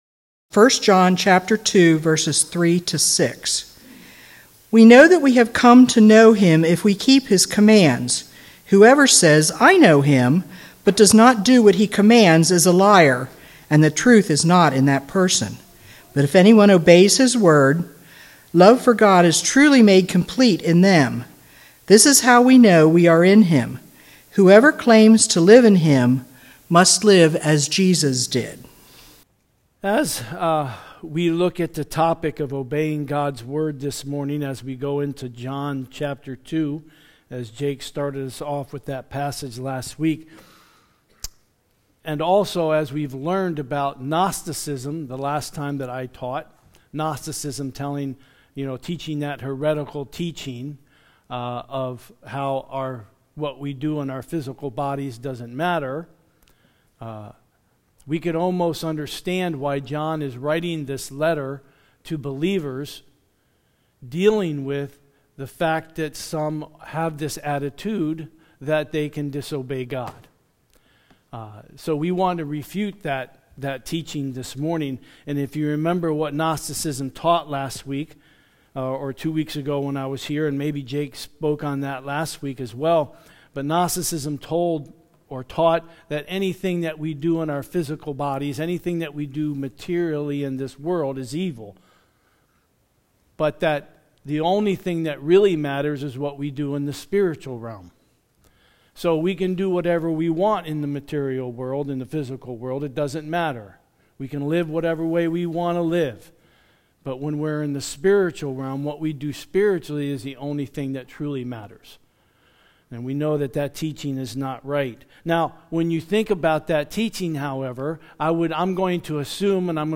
SERMONS » Conestoga Bethel